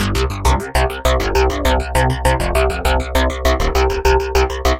描述：疯狂的合成器槽。 使用了立体声延迟。 在Logic中创作。
Tag: 100 bpm Techno Loops Synth Loops 827.59 KB wav Key : Unknown